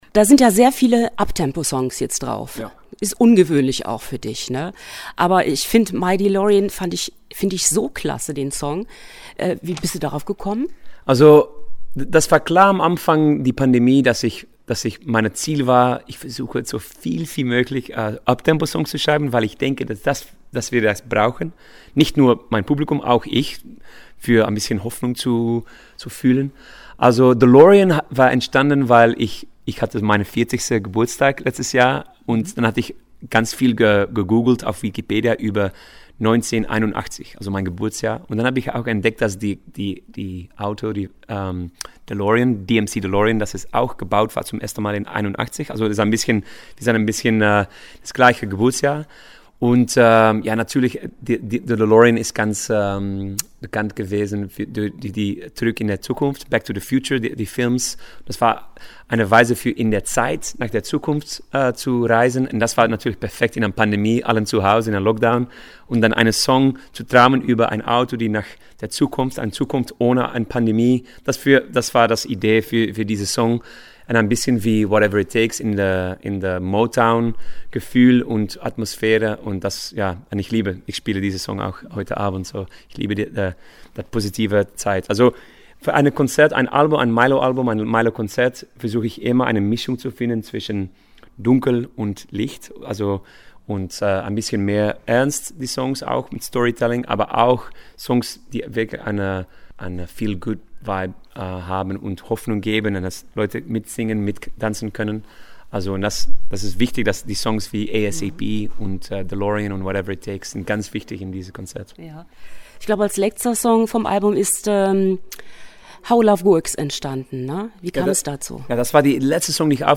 Milow im Interview – Radio Contact – Ostbelgien NOW